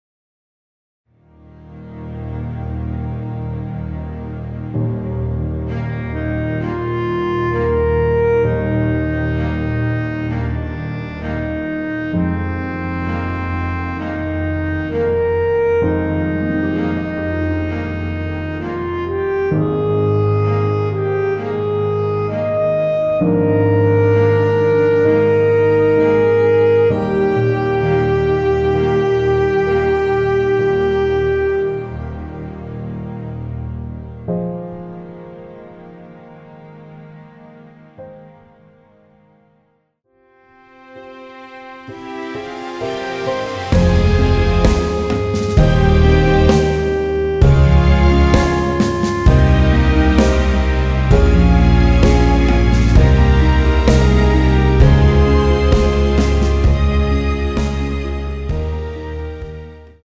원키에서(-1)내린 MR입니다.
Eb
앞부분30초, 뒷부분30초씩 편집해서 올려 드리고 있습니다.
중간에 음이 끈어지고 다시 나오는 이유는